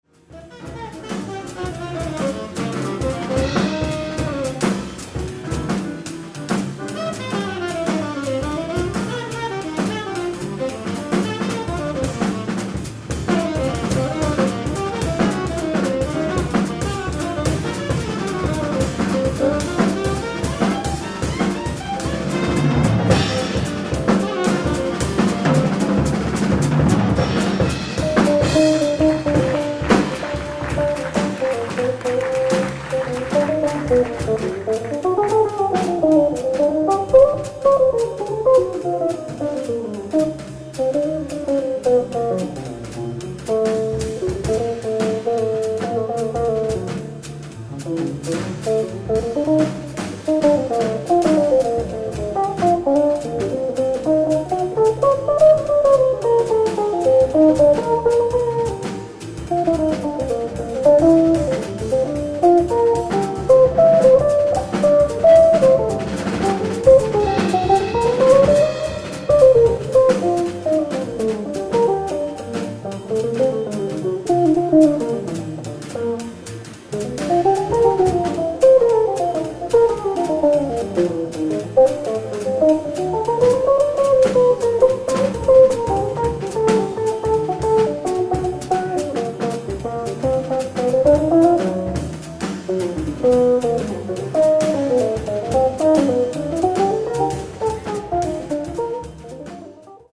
ライブ・アット・バードランド、ニューヨーク 02/04/1997
ＮＹ、バードランドでのライブ！！
※試聴用に実際より音質を落としています。